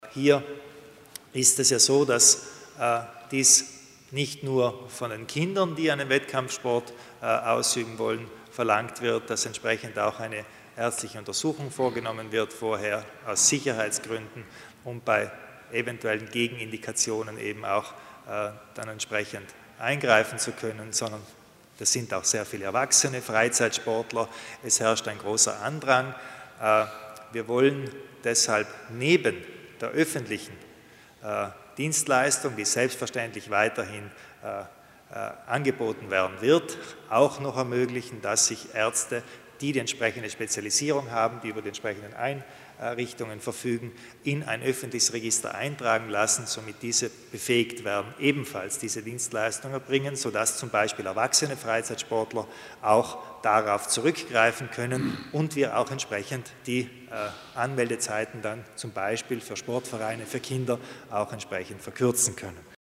Landeshauptmann Kompatscher zu den Neuheiten in Sachen Sportmedizin